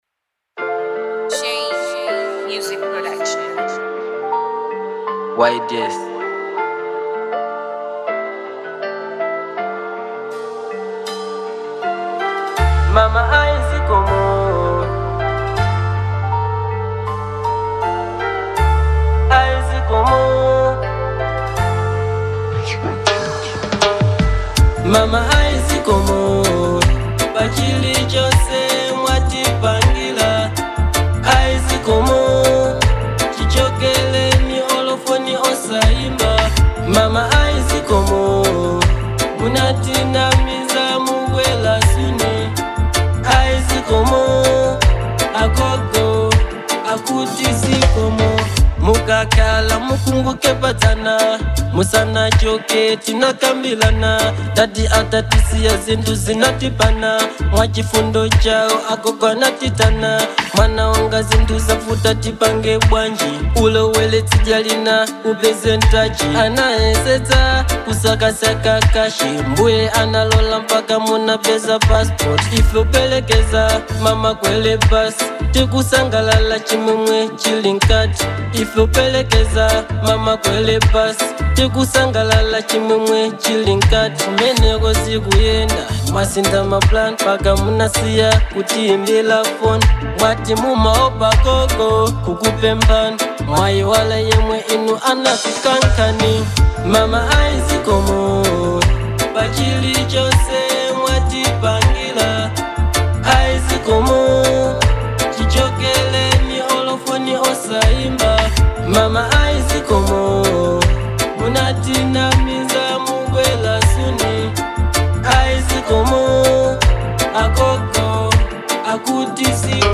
Genre : Dancehall